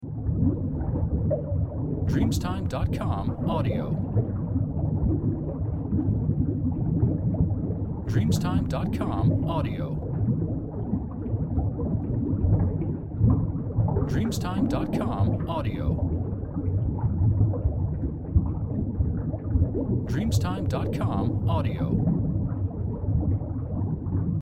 Unterwasserblasen